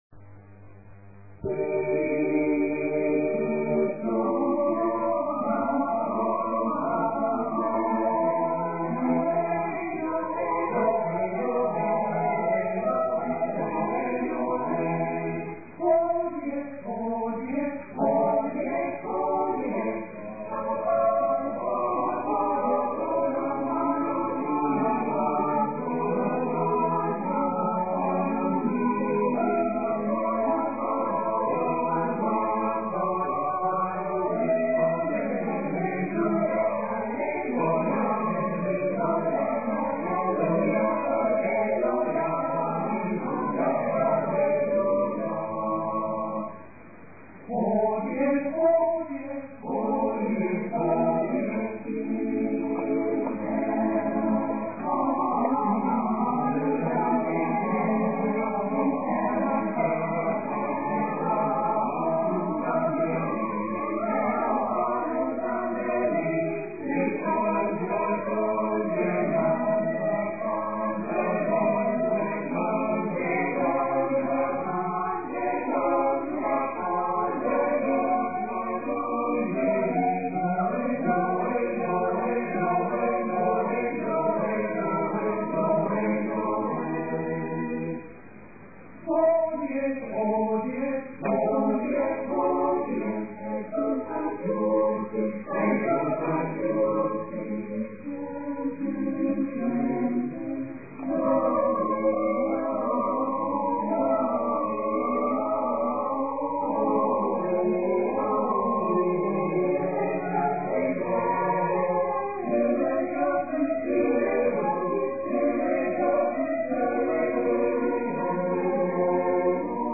Ashbourne Senior Choir:
Play image ... 2 Mins 50 Seconds (167 KB 8 Kbits/second 11,025 Hz), recorded at rehearsals with an approx. 30 second loading time.